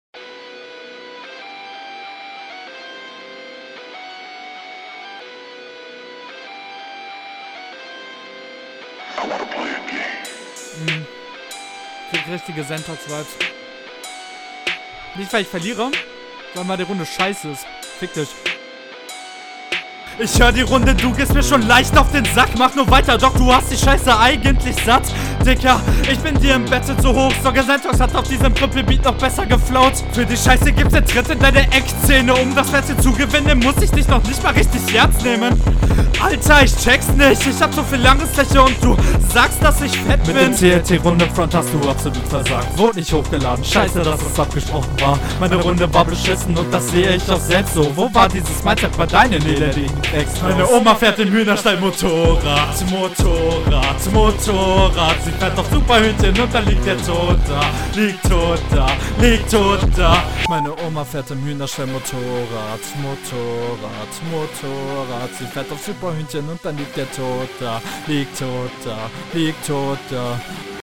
Ihr beide kommt nicht so geil auf den Beat. naja ist auch schwer weil dort …
Hook ist absolut klasse, musste laut lachen.